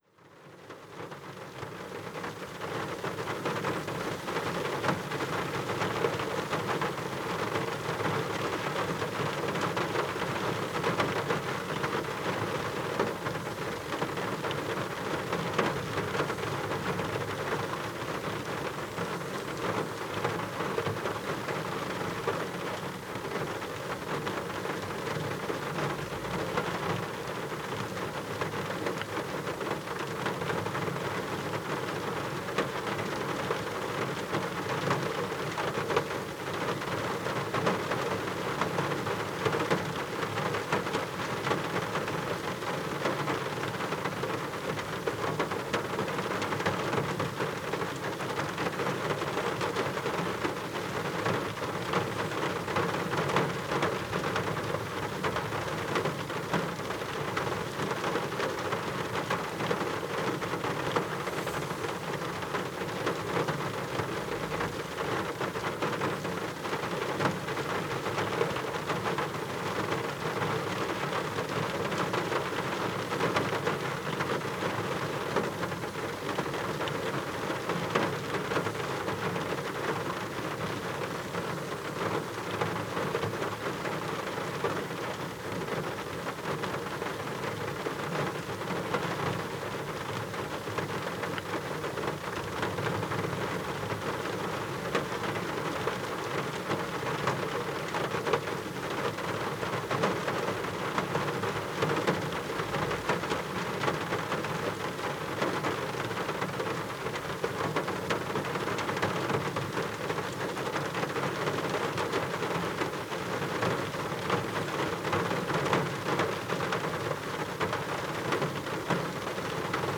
Rain On A Car Roof | Free MP3 Download
I shot this audio whilst car camping somewhere along Scotland's famous North Coast 500 route. As a storm crept in from the North Atlantic, I parked up for the night to enjoy hours of cosy rain snugged up in my car.
Here we have 10 minutes sleepy ambience of cosy rain on a car for sleep, relaxing, study or medidation.
rain-on-car-roof-10m.mp3